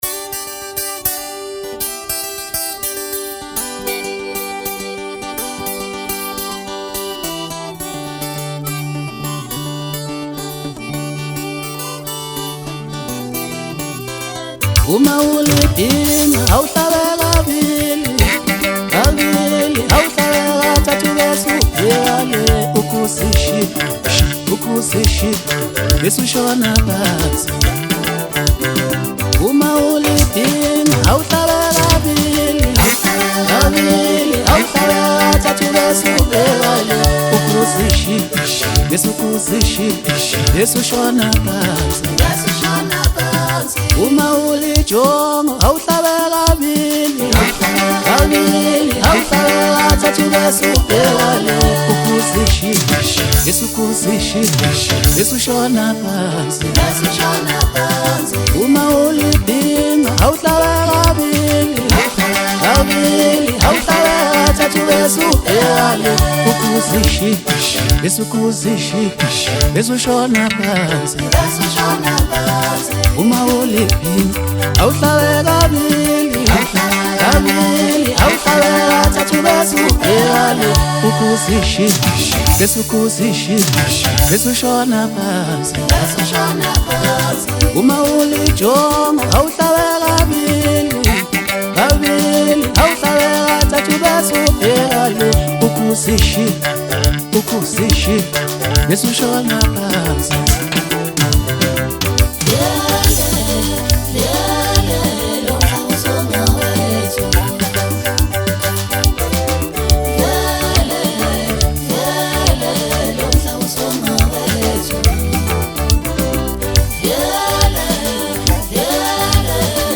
Maskandi
the traditional vibes are hard with this one